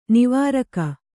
♪ nivāraka